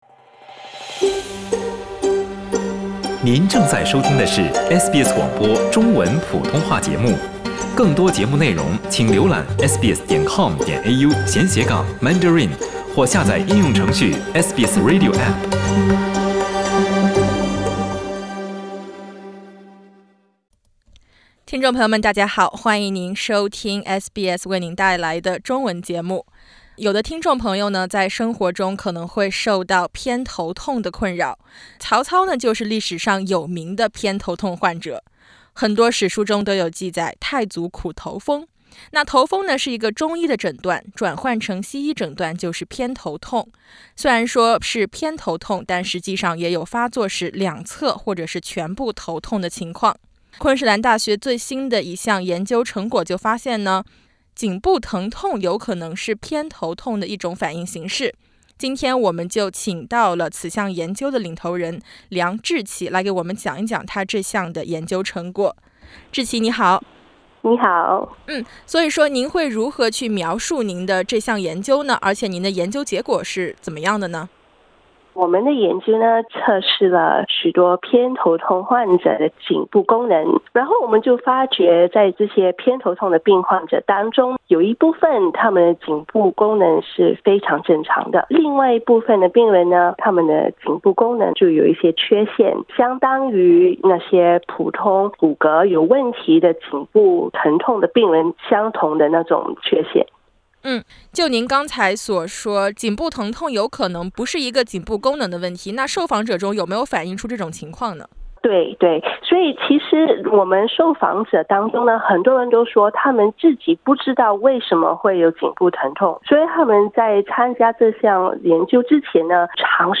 READ MORE 澳大利亚COVID-19每日要闻：新州扩大优先疫苗接种范围并放宽婚礼限制 请点击封面图片，收听完整采访。